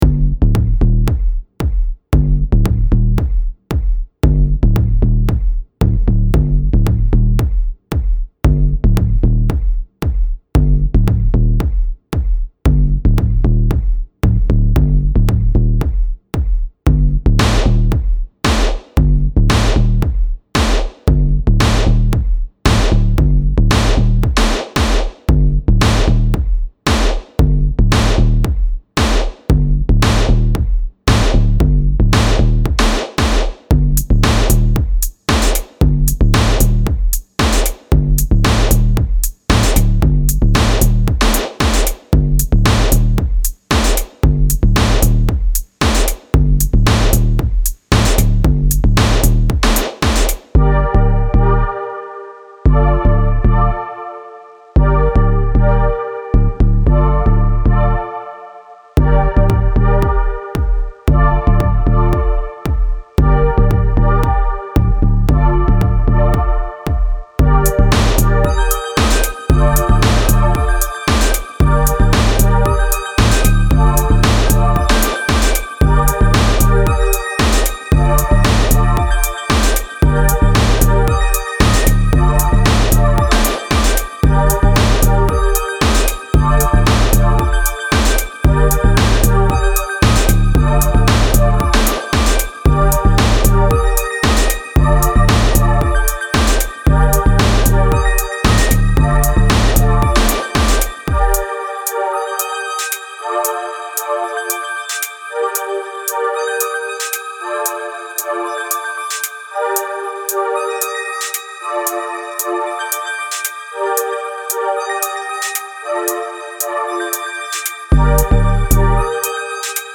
Style Style EDM/Electronic
Mood Mood Driving, Intense, Relaxed
Featured Featured Bass, Drums, Synth
BPM BPM 114